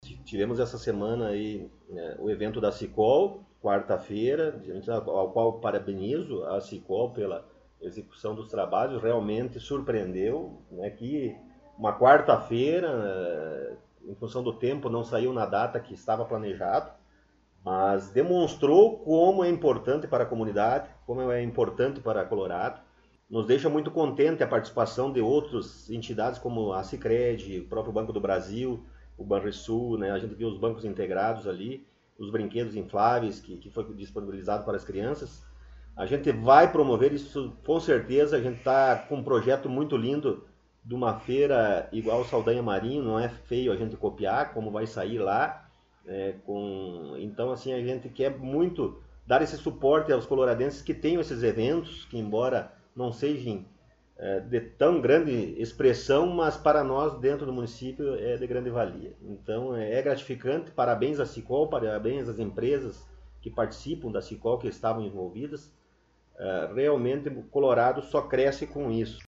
Prefeito Municipal Rodrigo Sartori concedeu entrevista